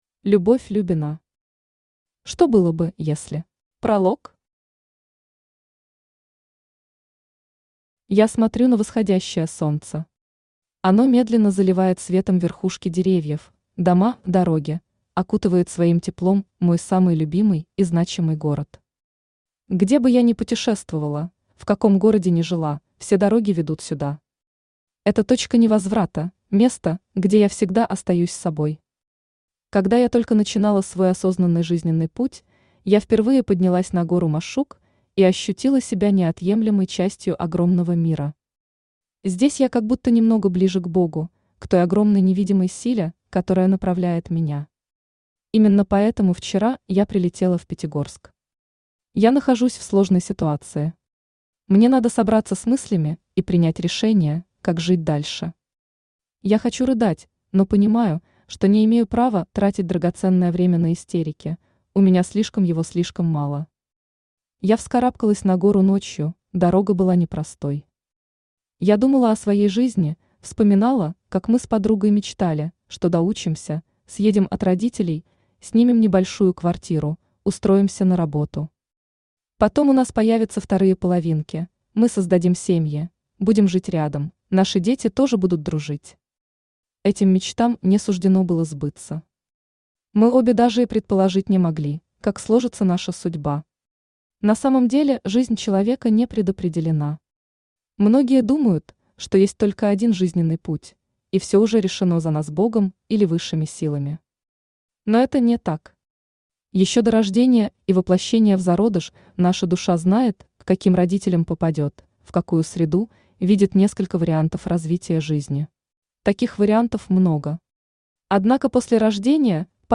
Аудиокнига Что было бы, если…
Автор Любовь Любина Читает аудиокнигу Авточтец ЛитРес.